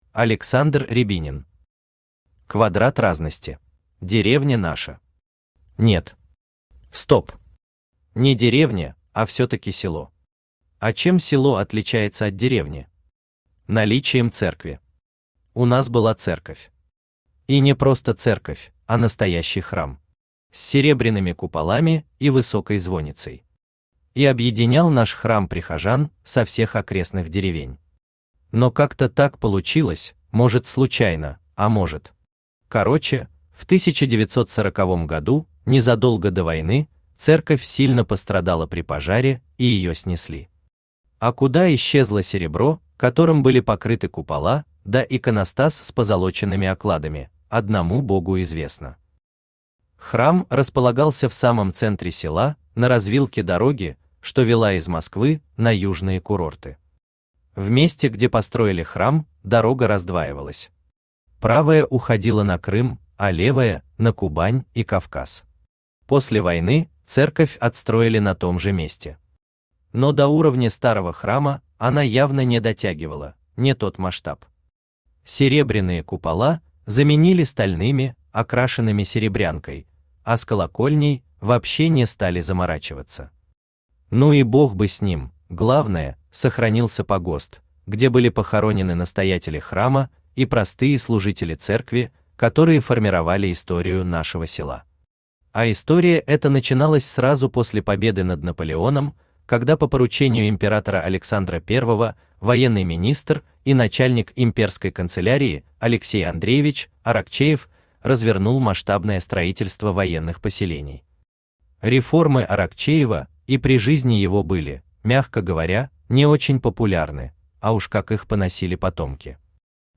Аудиокнига Квадрат Разности | Библиотека аудиокниг
Aудиокнига Квадрат Разности Автор Александр Рябинин Читает аудиокнигу Авточтец ЛитРес.